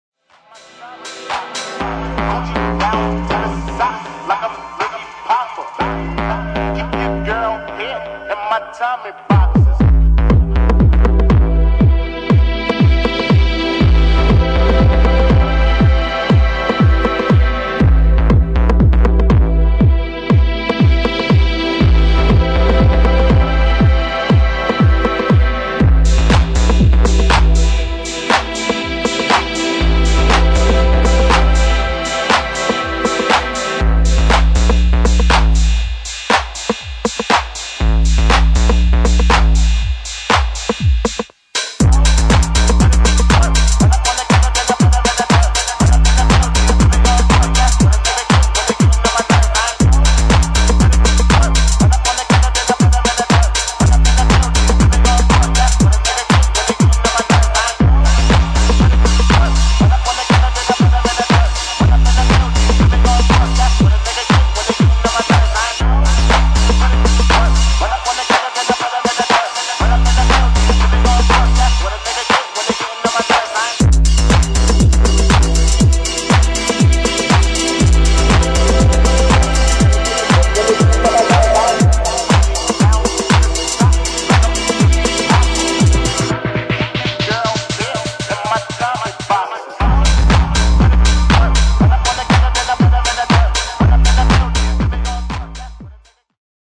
[ HOUSE | TECH HOUSE ]